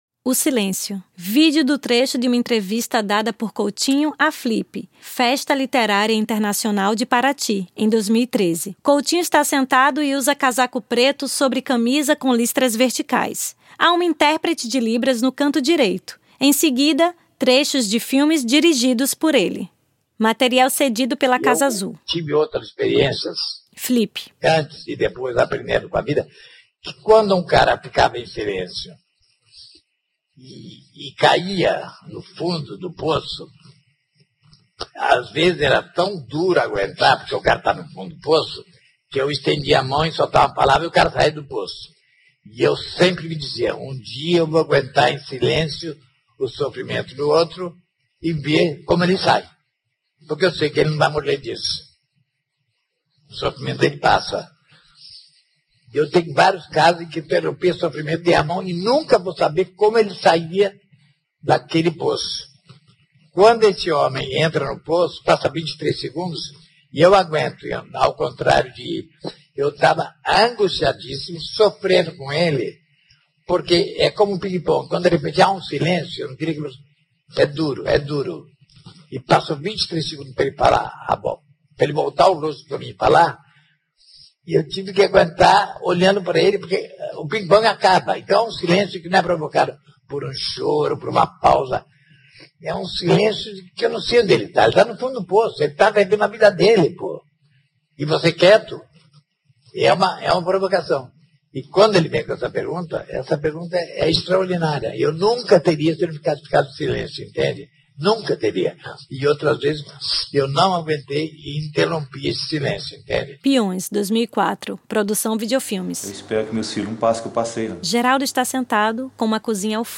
O Silêncio: entrevista e trechos de filmes
Trecho de uma entrevista dada por Eduardo Coutinho à Flip, Festa Literária Internacional de Paraty, em 2013. Em seguida, trechos de filmes dirigidos por ele.